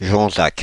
Jonzac (French pronunciation: [ʒɔ̃zak]
Fr-Paris--Jonzac.ogg.mp3